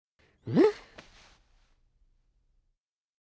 avatar_emotion_shrug.mp3